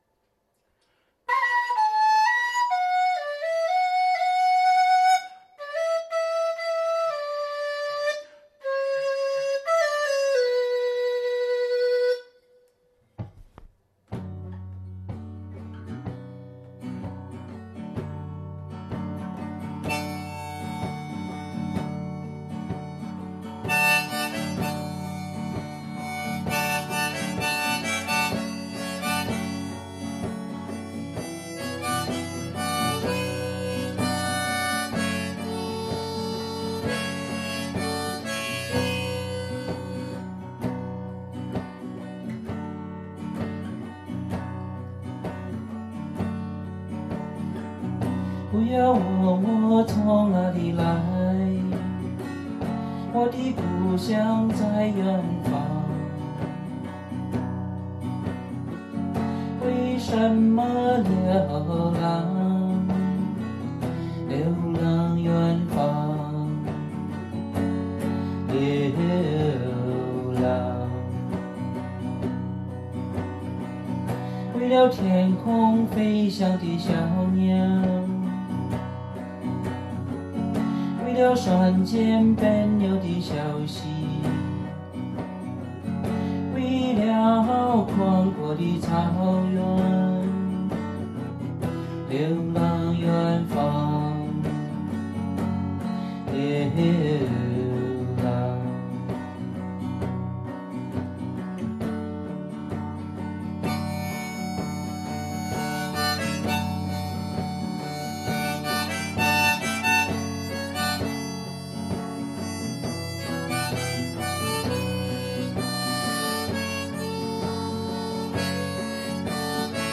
演唱/伴奏
圣诞节买了个比话筒还小的录音器，不需要软件和后期制作。录音效果不错，比手机录音好很多。